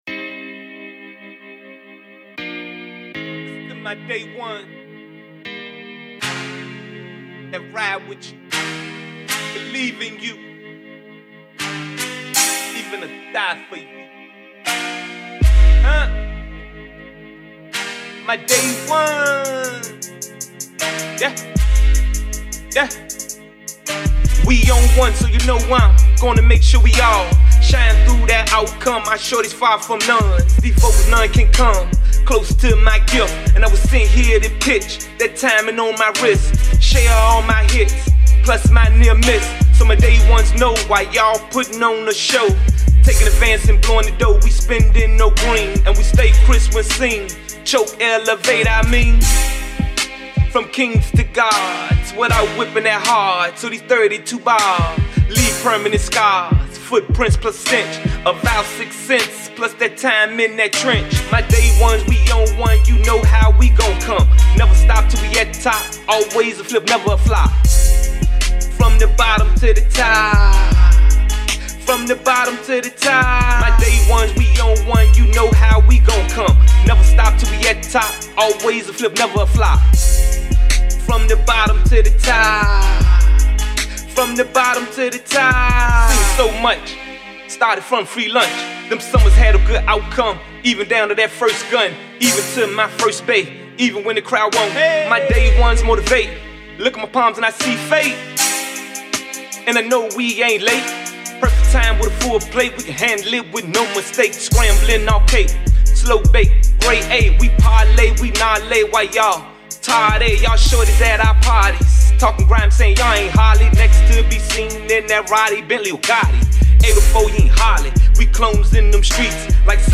Indie